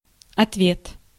Ääntäminen
Synonyymit accommodation Ääntäminen France: IPA: [ʁe.pɔ̃s] Haettu sana löytyi näillä lähdekielillä: ranska Käännös Ääninäyte 1. отве́т {m} (otvét) 2. ответ {m} (otvet) Suku: f .